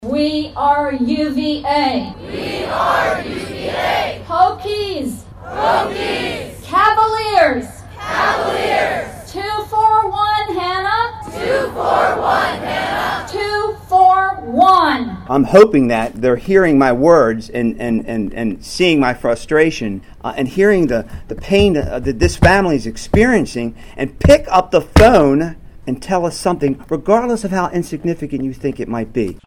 WINA-BEST-COVERAGE-OF-A-CONTINUING-NEWS-STORY-30-SEC-EXCERPT1.mp3